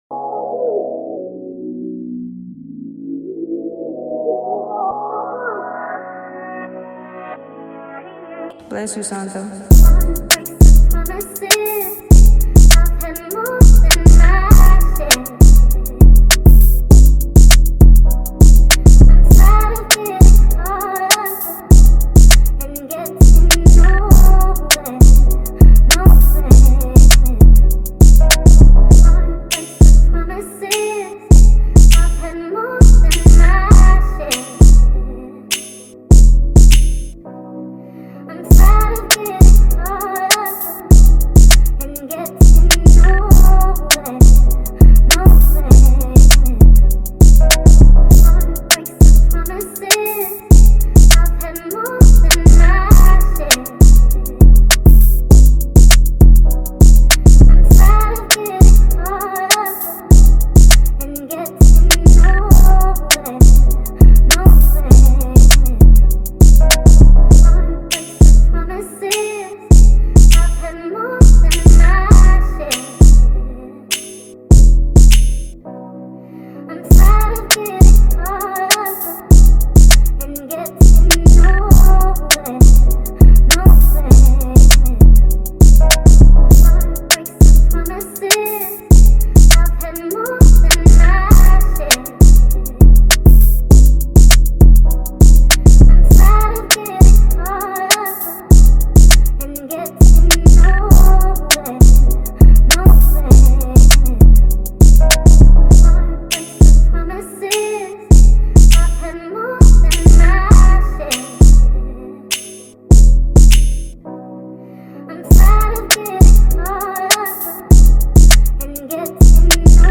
2023 in Detroit Instrumentals